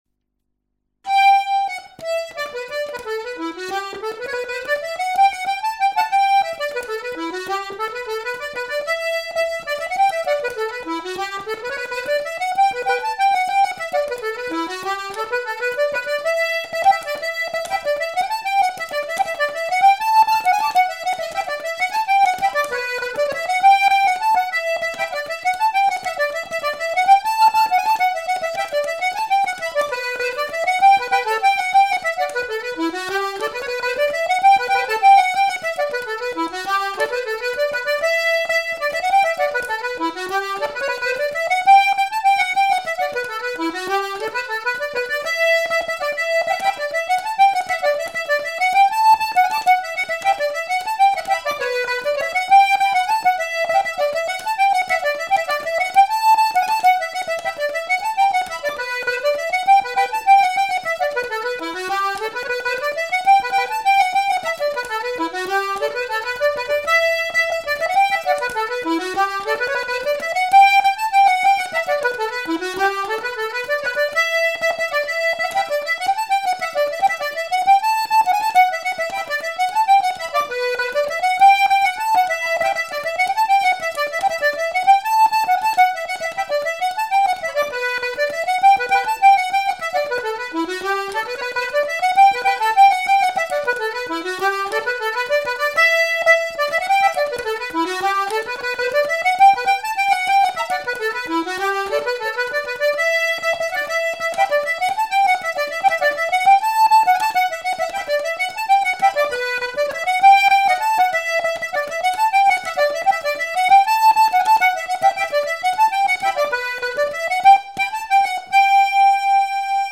Recorded in 1989 in Sullane, Co. Cork.